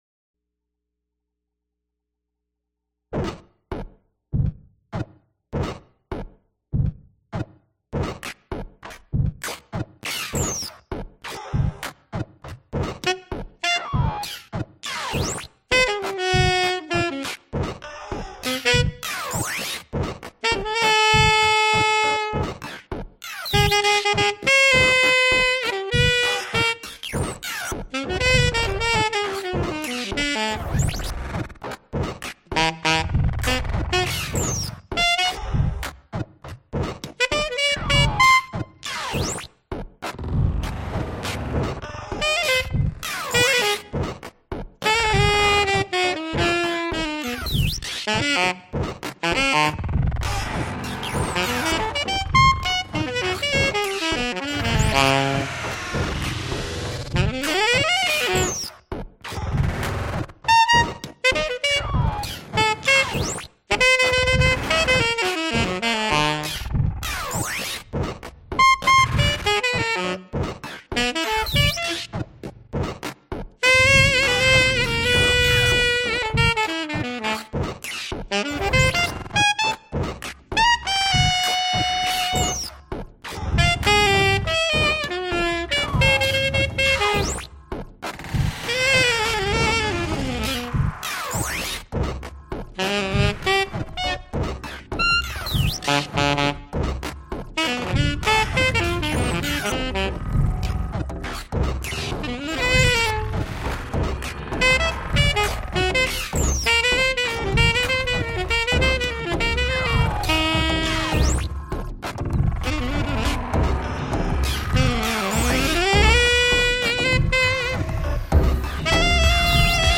alto and tenor sax, bass flute and electronics